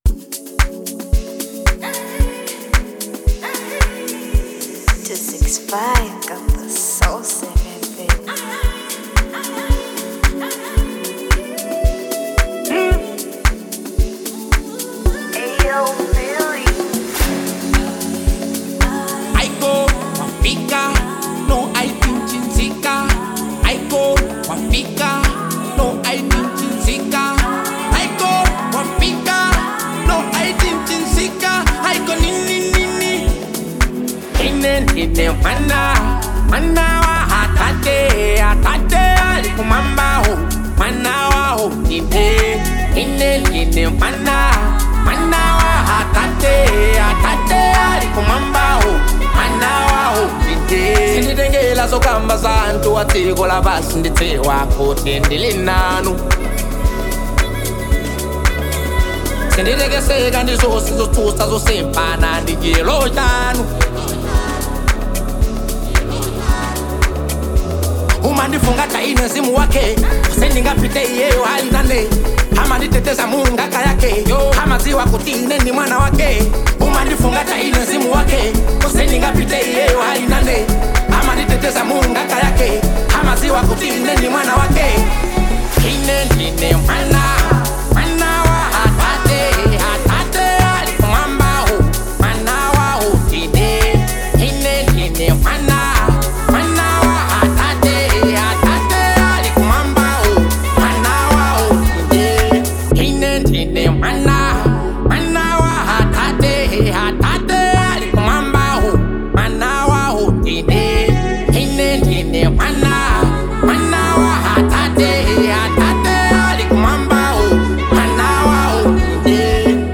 Genre : Amapiano